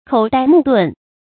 口呆目鈍 注音： ㄎㄡˇ ㄉㄞ ㄇㄨˋ ㄉㄨㄣˋ 讀音讀法： 意思解釋： 見「口呆目瞪」。